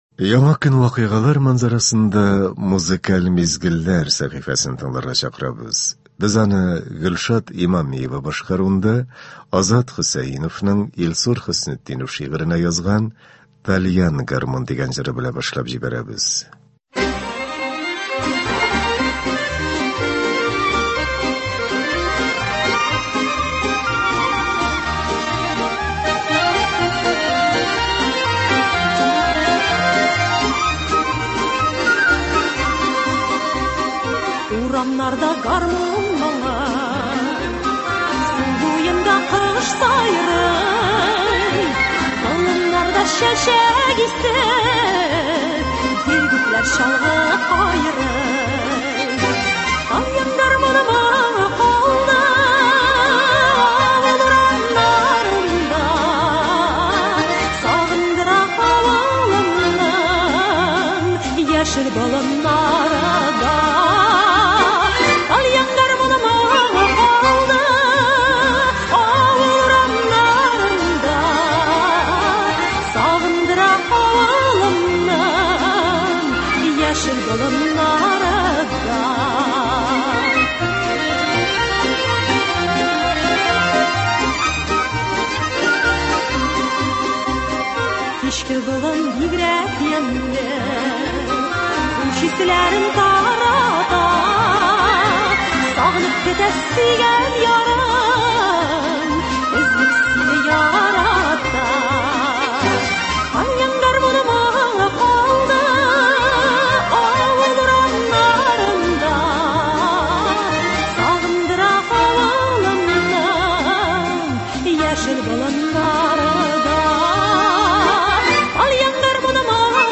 Көзге иртәне моңлы җырлар белән башлыйбыз